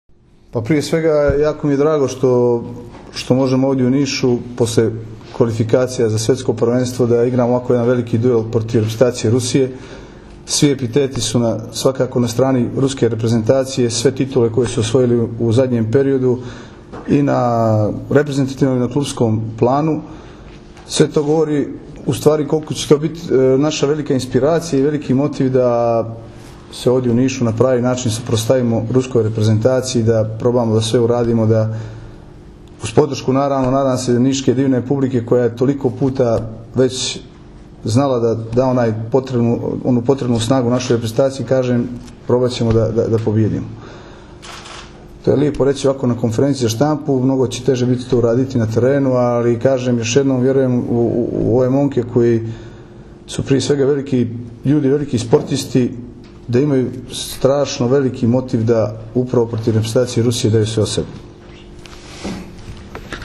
U hotelu “Tami” u Nišu danas je održana konferencija za novinare povodom utakmica I vikenda B grupe I divizije XXV Svetske lige 2014.
IZJAVA IGORA KOLAKOVIĆA